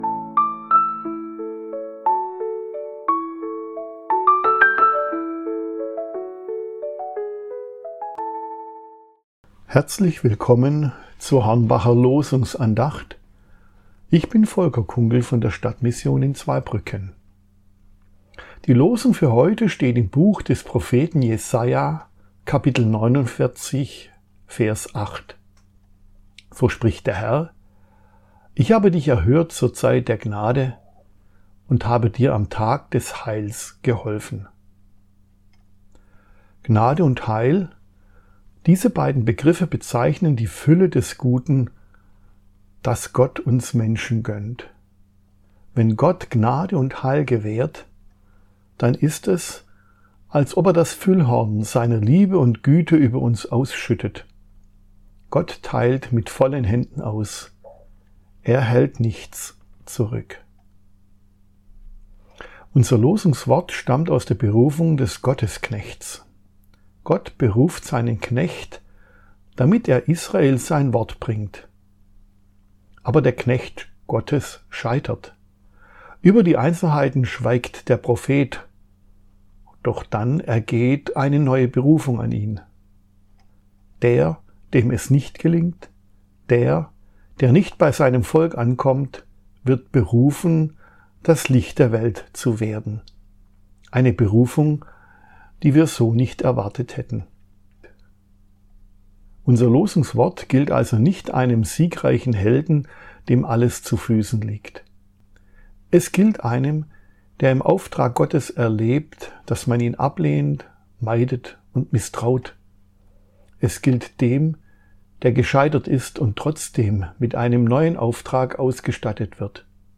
Losungsandachten – Seite 12 – Prot.